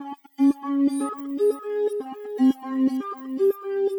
Lab Work (Lead) 120BPM.wav